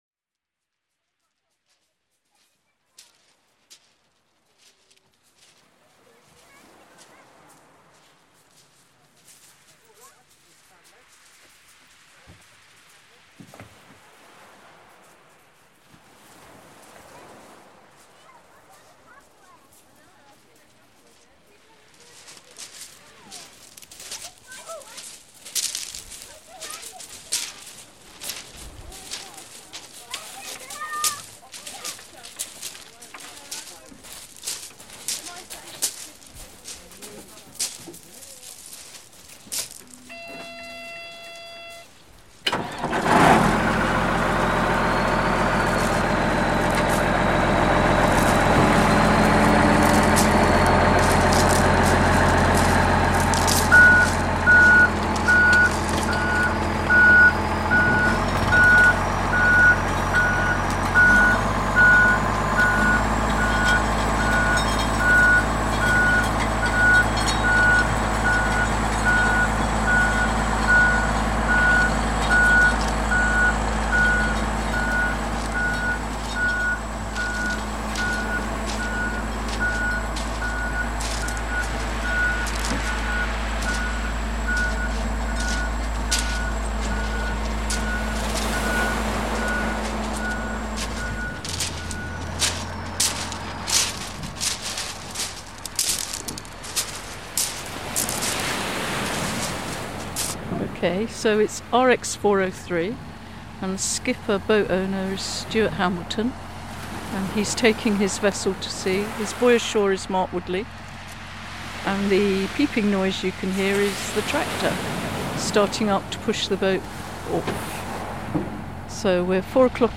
his recording, made in January 2025, is of one of the fishing boats being launched from the beach in Hastings.
The recording was made as part of a 15 year project by myself working with Hastings Fishermens Protection Society to capture the oral history, and soundscapes of the fleet to create an archive for now and the future.